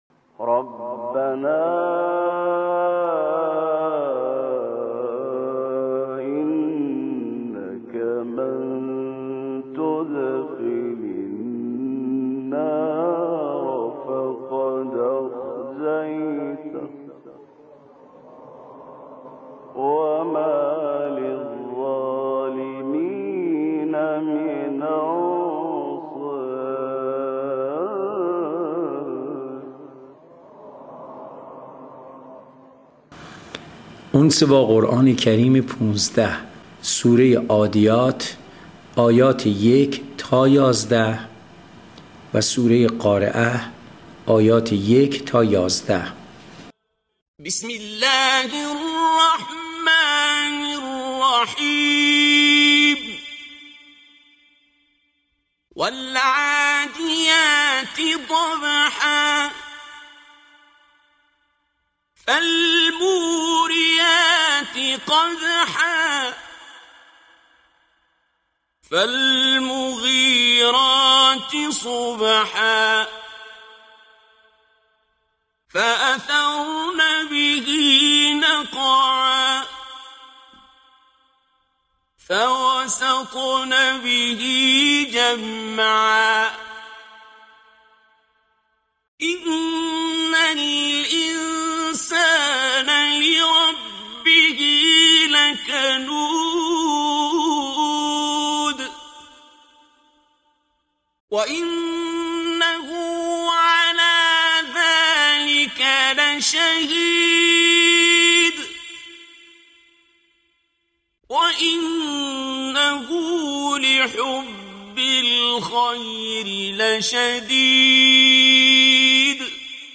قرائت آیات سوره های عادیات و قارعه